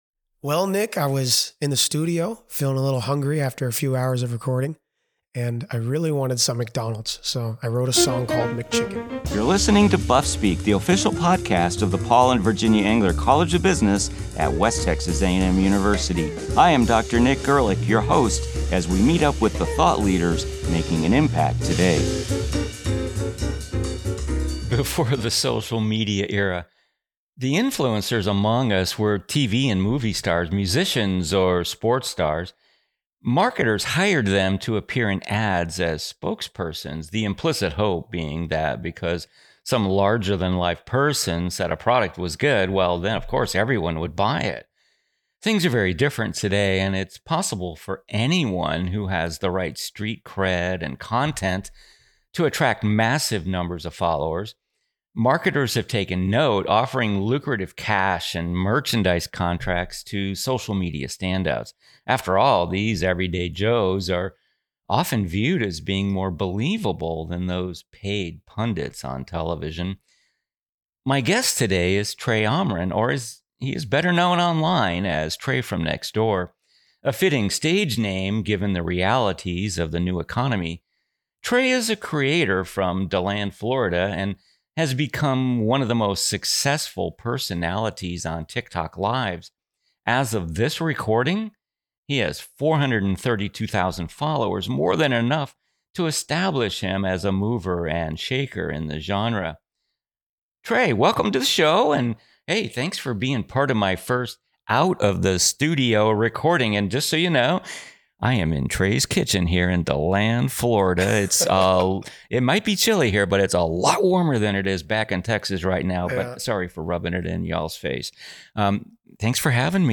Everyone wants to be an influencer these days, but the real money is in livestreaming. Tune in to a convo with a livestream phenom who has fans literally throwing money at him.